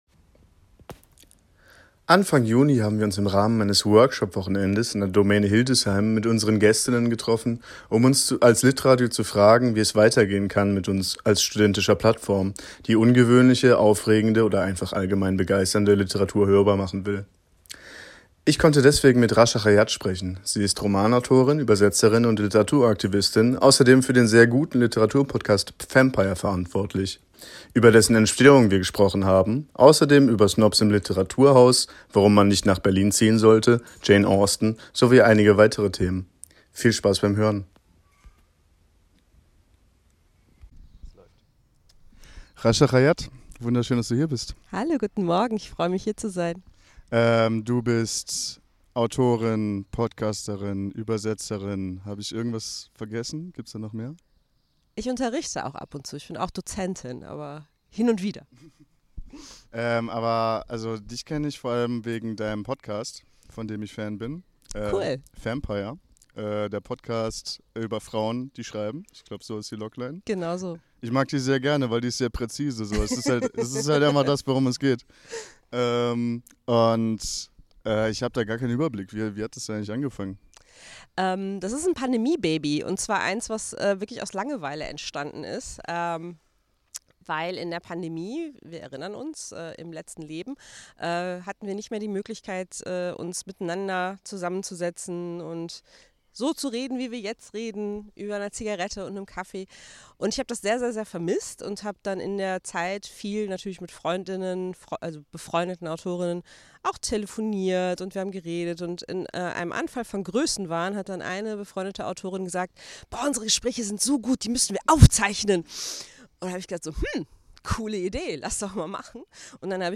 Lesen, Casten, Reden